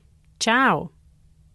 Just click on the languages below to hear how to pronounce “Hello”.